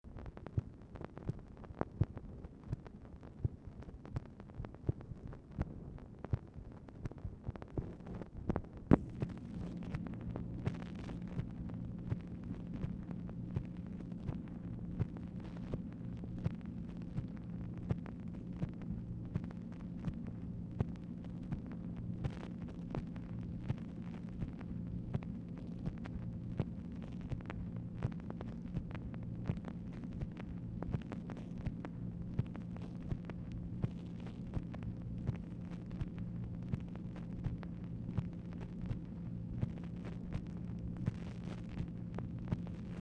Telephone conversation # 9524, sound recording, MACHINE NOISE, 1/21/1966, time unknown | Discover LBJ
Dictation belt